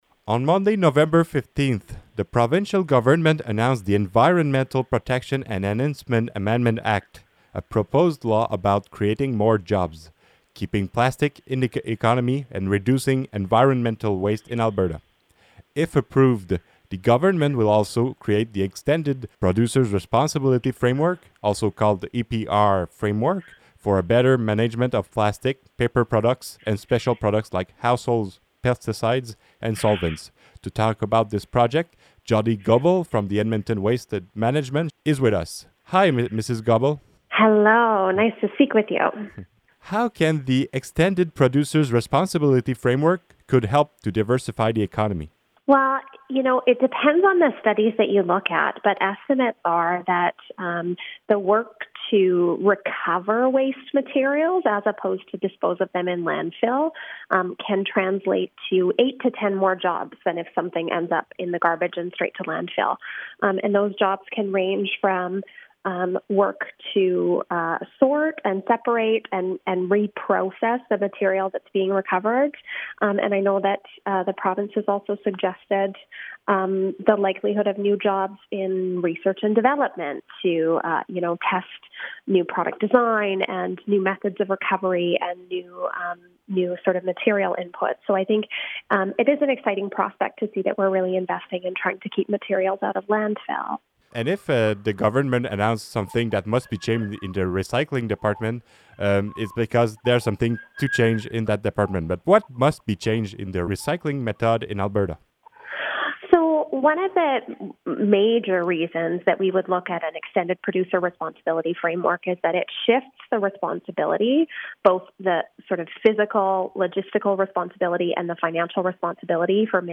L'entrevue a été réalisée en anglais.